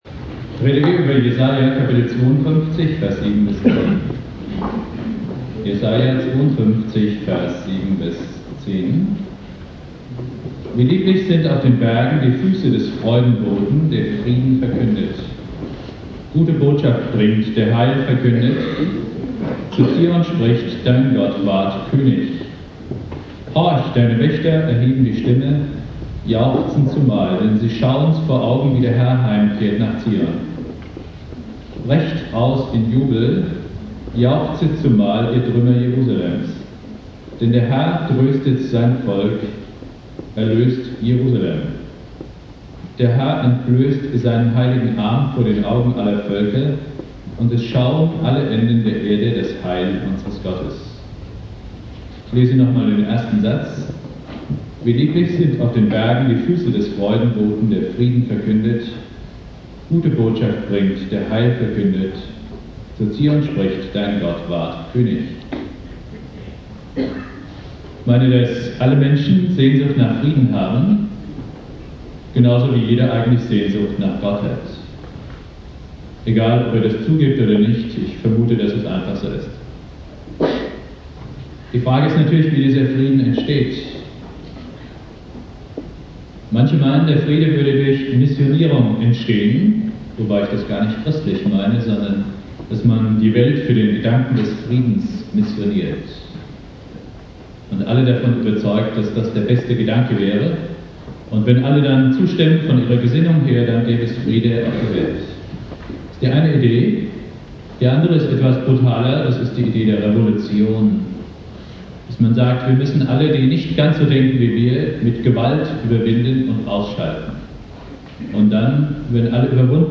Predigt
Thema: "Dein Gott ward König" (mit Außenmikro aufgenommen) Bibeltext: Jesaja 52,7-10 Dauer